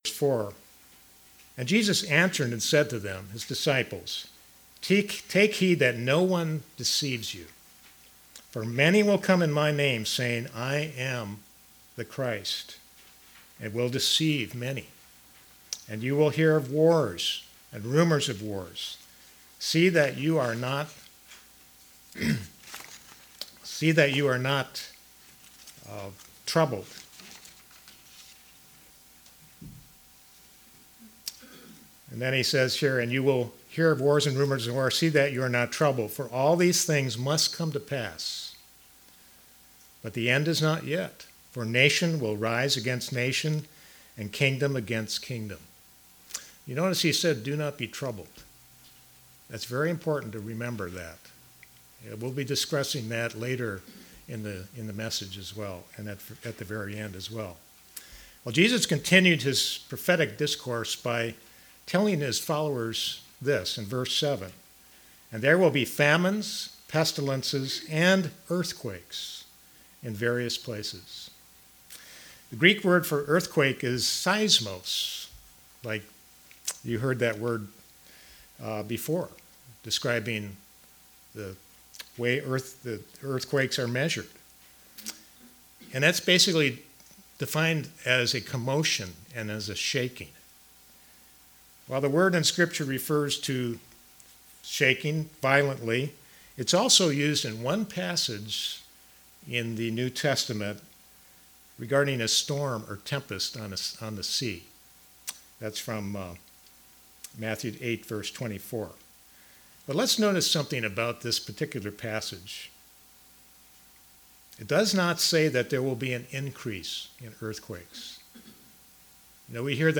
Given in Kingsport, TN Knoxville, TN London, KY